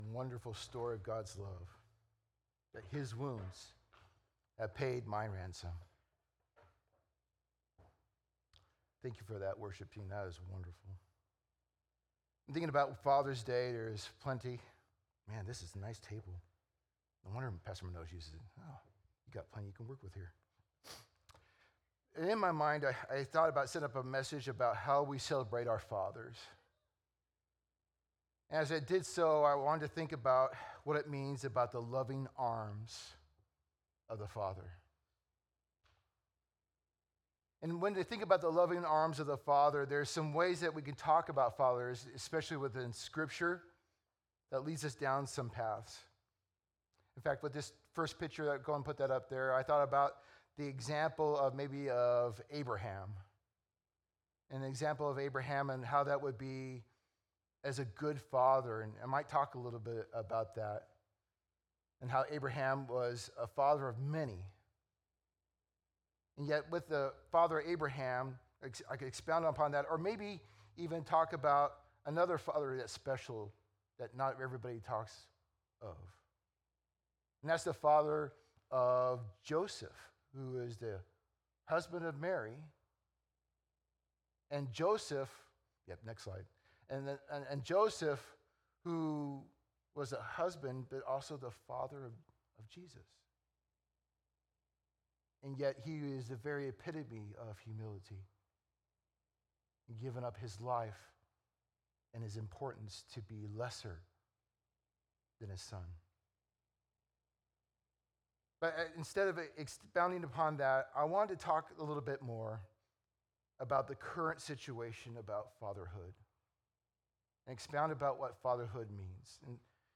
June 15th, 2025 - Sunday Service - Wasilla Lake Church